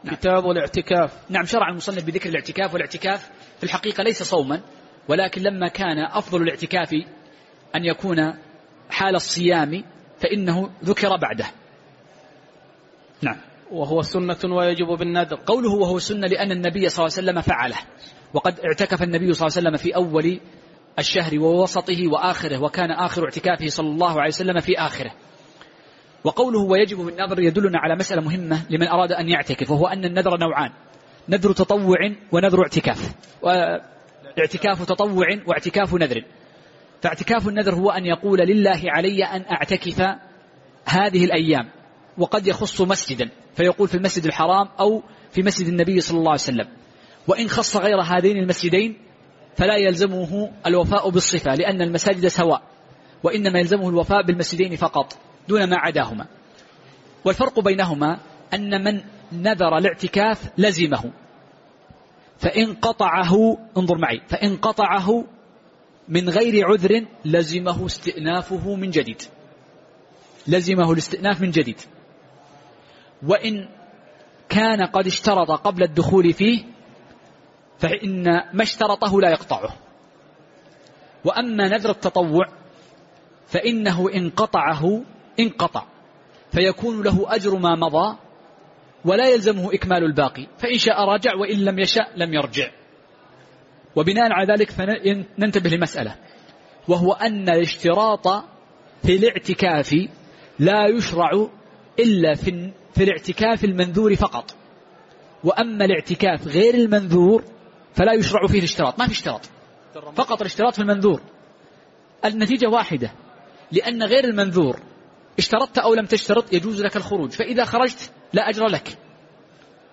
تاريخ النشر ١٣ جمادى الأولى ١٤٤١ هـ المكان: المسجد النبوي الشيخ